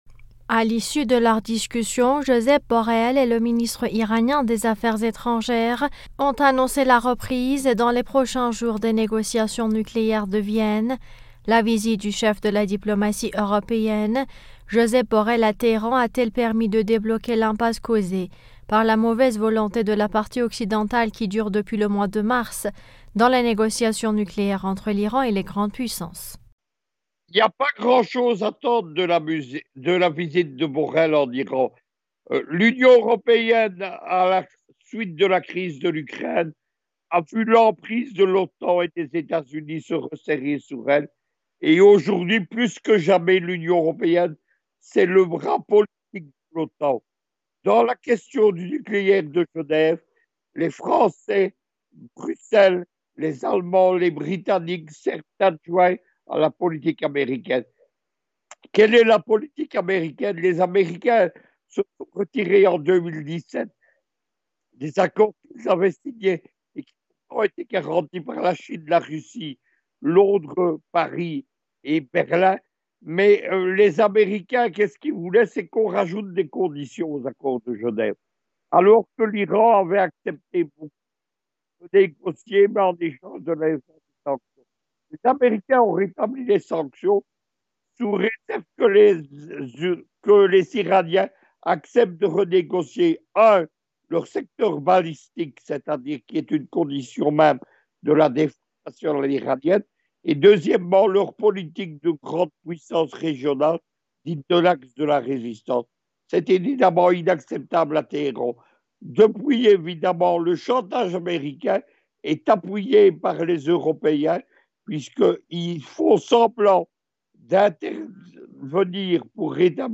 géopoliticien, nous en dit plus.